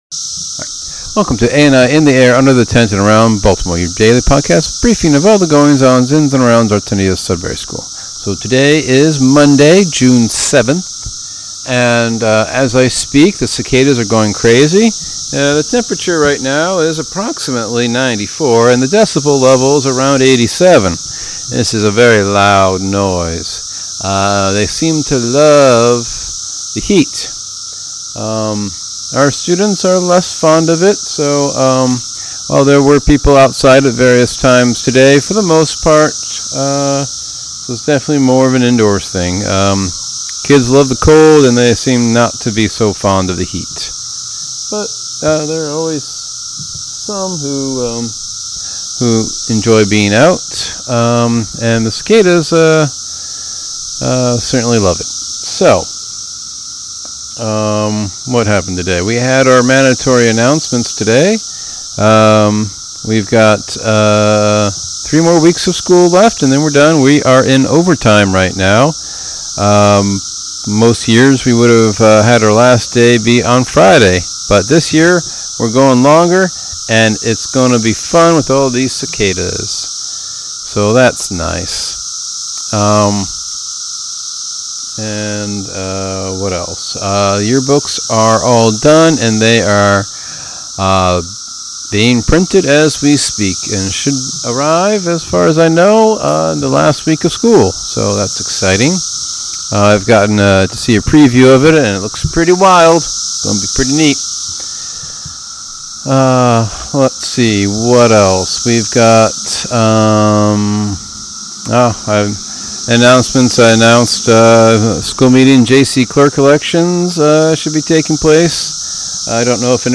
Cicadas going crazy - 94°F, 87 decibels.